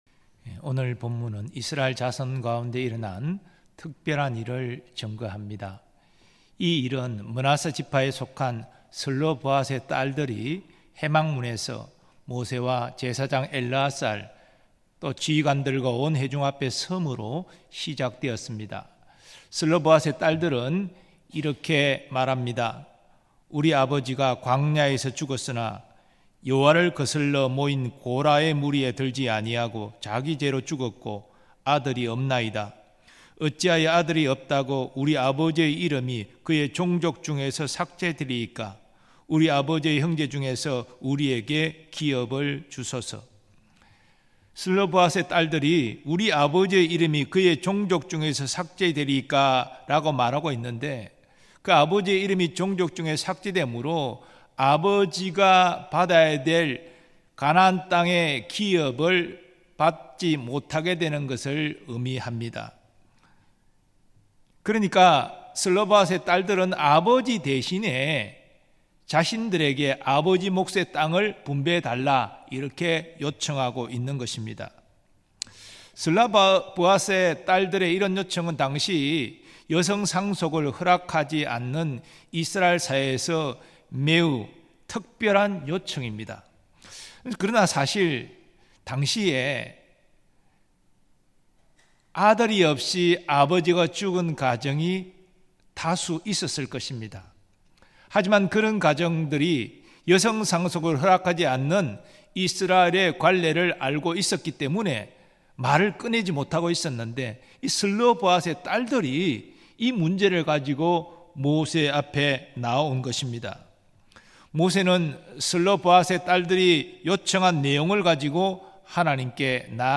2024년 8월 7일 삼일예배 말씀
믿음의 기도에 하나님의 응답이 있습니다 음성설교 듣기 MP3 다운로드 목록 이전 다음